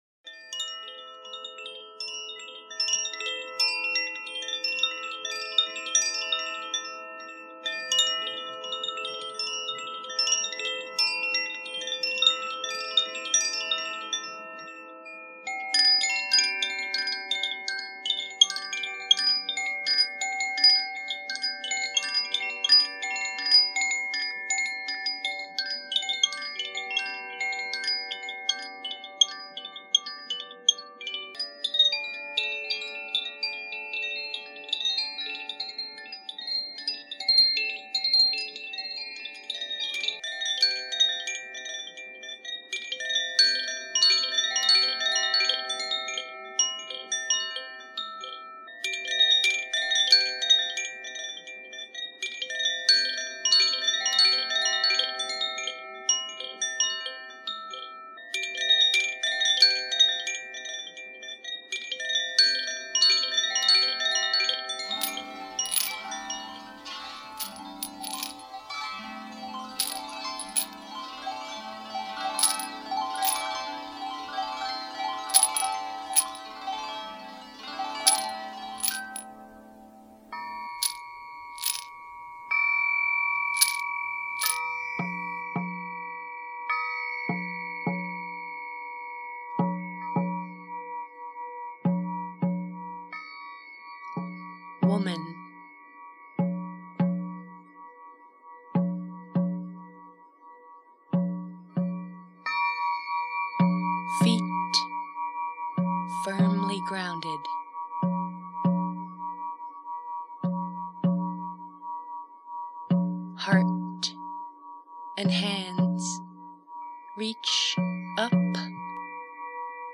Set of 4 Koshi Chimes
Moon Harp, Ceramic Heart Rattle
6″ Crystal Singing Pyramid, Deerskin Frame Drum
Spirit Flute 432HZ Bass in E Minor